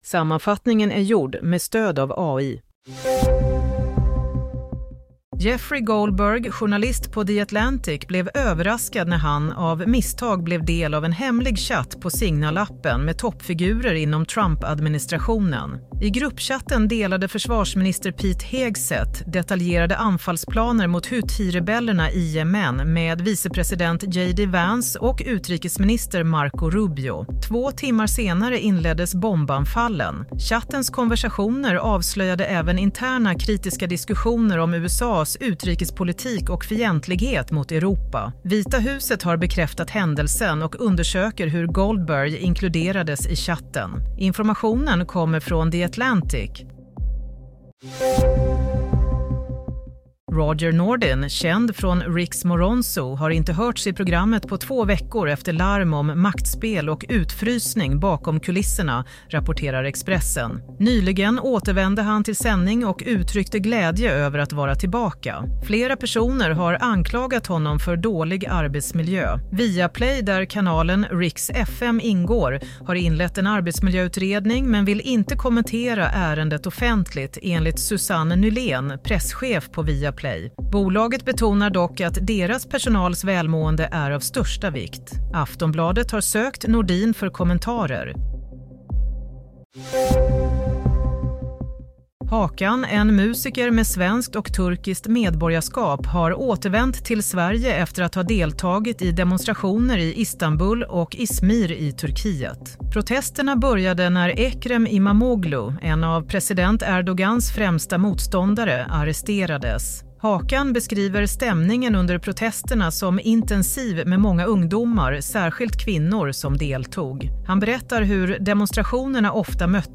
Nyhetssammanfattning - 24 mars 22:00